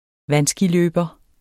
Udtale [ ˈvanˌsgiˌløˀbʌ ] Betydninger person der står på vandski